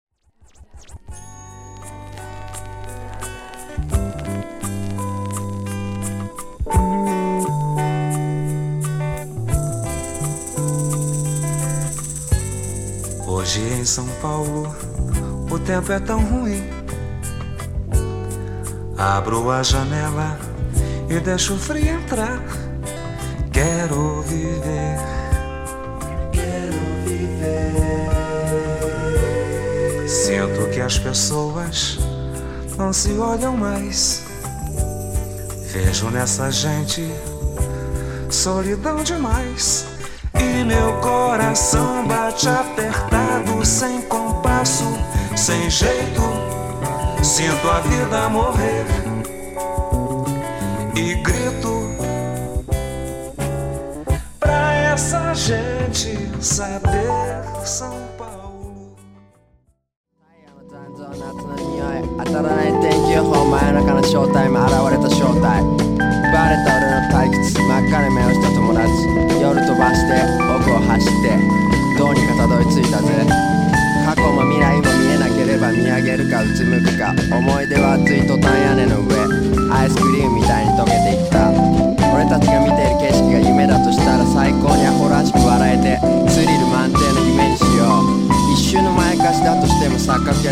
FREE SOUL# NORTHERN / MODERN# SSW / FOLK# CLUB
DJ/プロデューサー